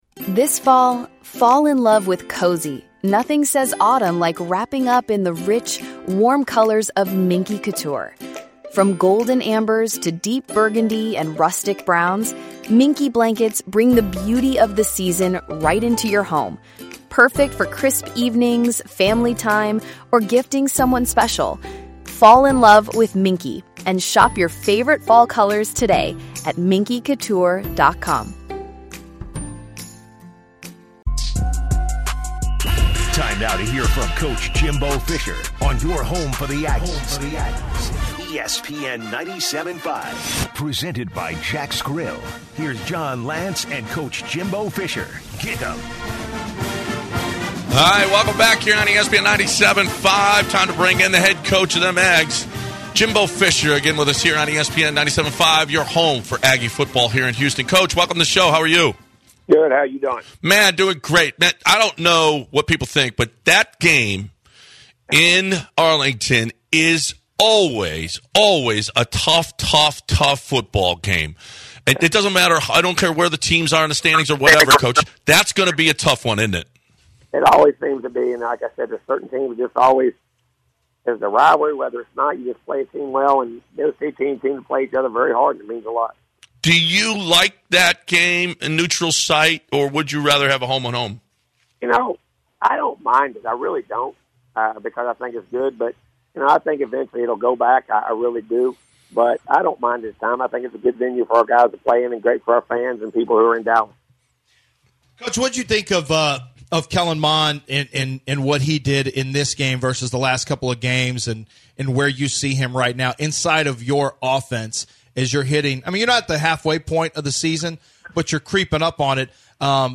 Jimbo Fisher joins The Bench to recap the Aggies win over the Razorbacks